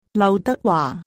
Lau Dak-wa